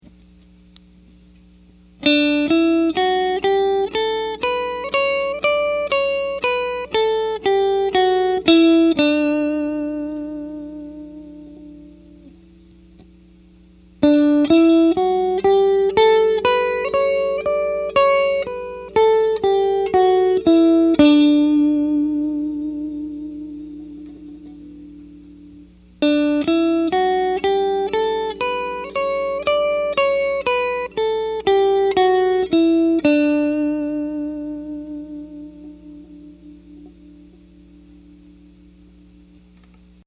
In the sample, I play a scale in E major starting on the 3rd string on the 9th fret. The first clip is with the Dunlop Tortex Medium (Orange), to provide a reference point. The second clip is played with the V-Picks Medium Rounded, and the third clip is played with V-Picks Medium Pointed.
If you listen carefully, the difference in how the clips sound is subtle, but important. With the Tortex, there is actually a very slight delay between when a string is struck and when sound is produced.
I got the same bright tone that the Tortex produced, but the tone is much smoother and more refined and defined.
Even though I stayed on tempo (I had a metronome set at 120bps that I was playing to), I no longer felt confident with that pick.
I was even a bit sloppy with the second clip, using the medium rounded, but the tone was so much smoother than the Tortex, despite my technical mistakes.